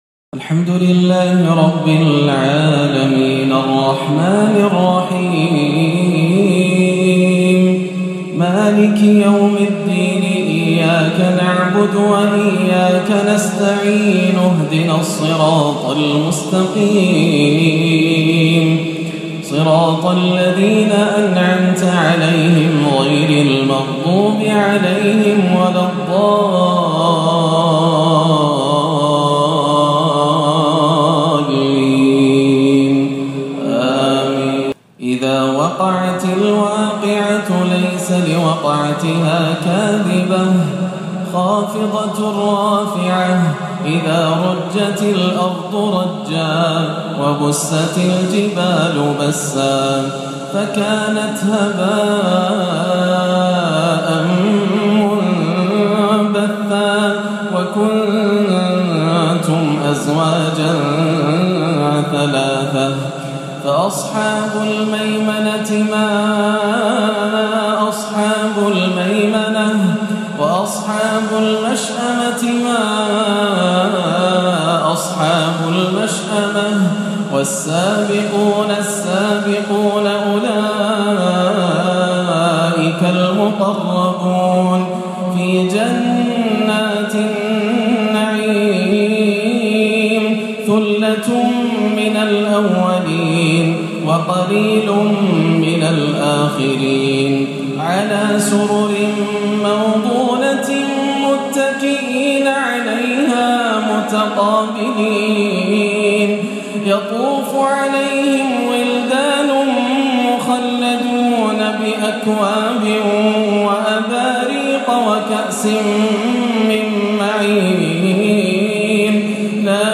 "سورة الواقعة" عشائية تاريخيه مؤثرة متنوعة المقامات والأداء - السبت 17-11 > عام 1437 > الفروض - تلاوات ياسر الدوسري